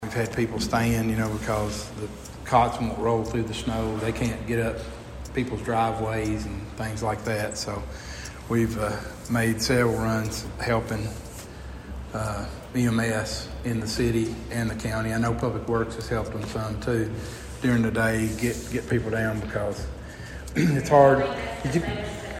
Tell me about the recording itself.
During Monday night’s meeting, the Princeton City Council moved forward with plans for sidewalk repairs, received updates on the winter storm’s impact, and learned that a longtime city employee is stepping down.